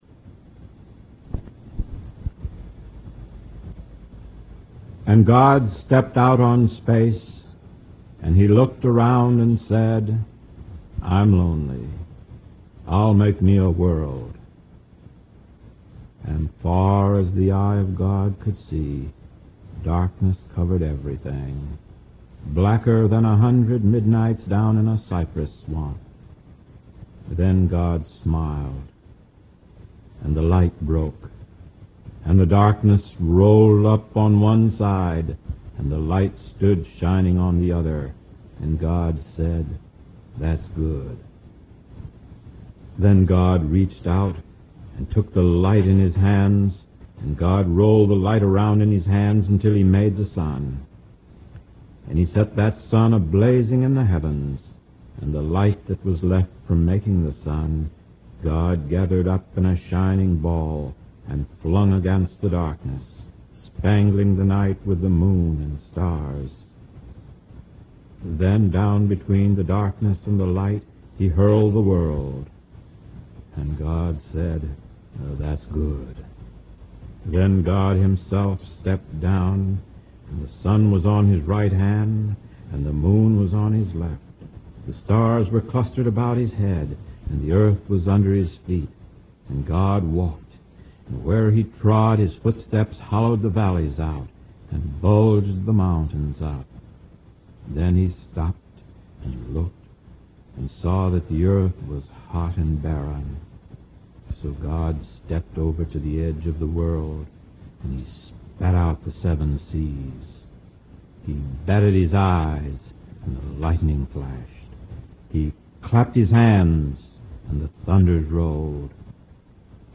Reading: